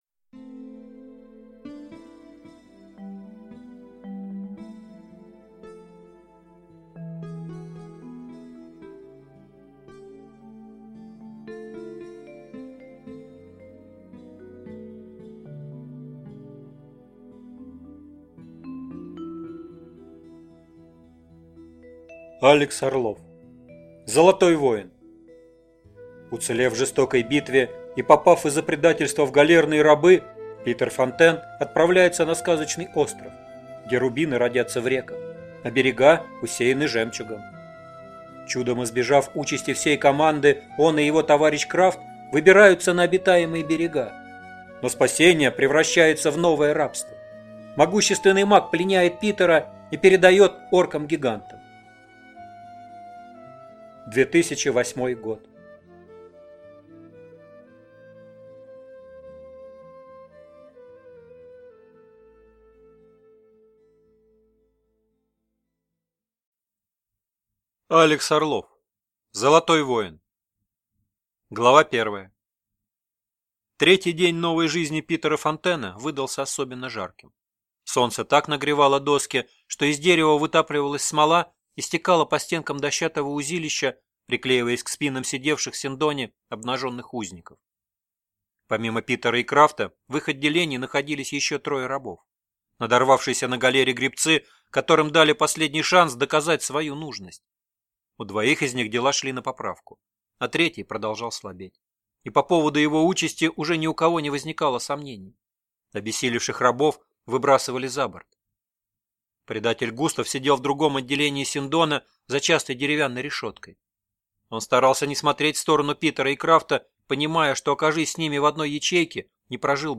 Аудиокнига Золотой воин | Библиотека аудиокниг